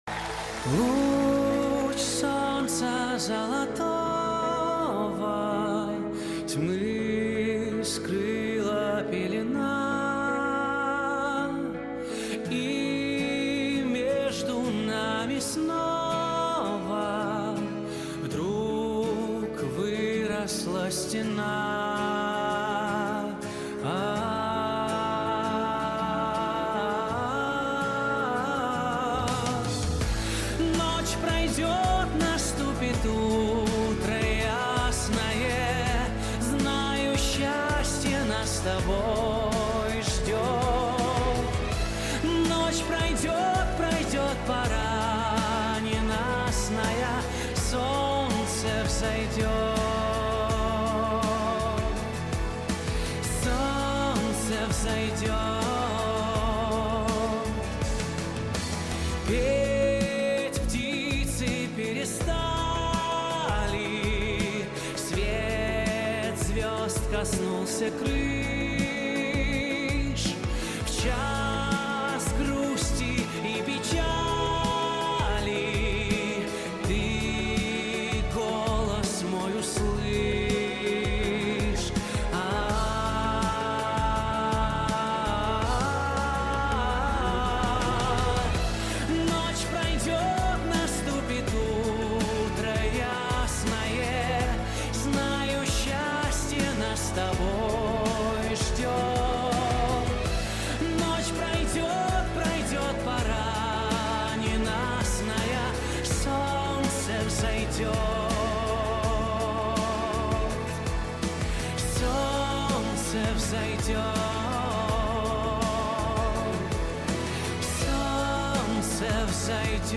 Концерт